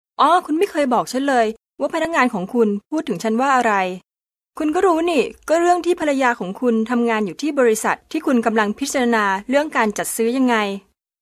Sprecherin thailändisch für TV / Rundfunk /Industrie.
Sprechprobe: Werbung (Muttersprache):
Professionell female thai voice over artist